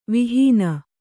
♪ vihīna